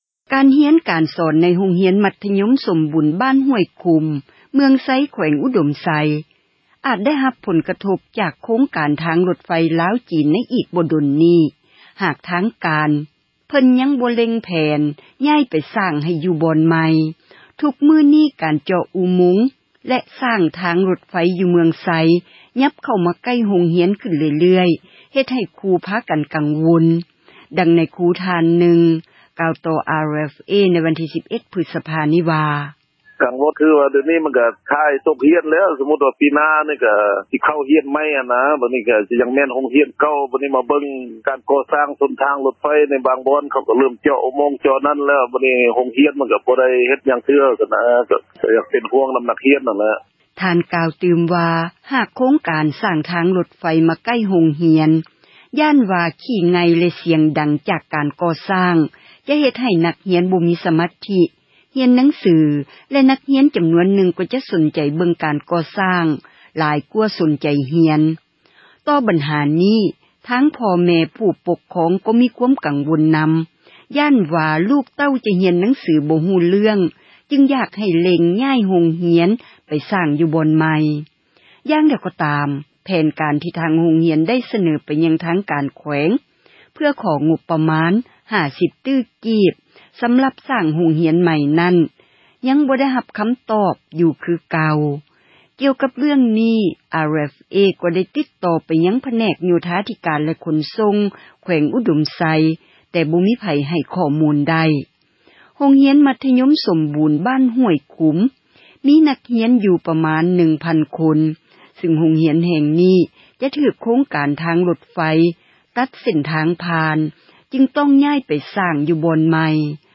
ທຸກມື້ນີ້ ການເຈາະອຸໂມງ ແລະ ສ້າງທາງຣົດໄຟ ຢູ່ເມືອງໄຊ ກໍເຂົ້າມາໃກ້ໂຮງຮຽນ ຂຶ້ນເລື້ອຍໆ ເຮັດໃຫ້ຄຣູ ພາກັນກັງວົນ, ດັ່ງນາຍຄຣູ ທ່ານນຶ່ງ ກ່າວຕໍ່ RFA ໃນວັນທີ 11 ພຶສພາ ນີ້ວ່າ: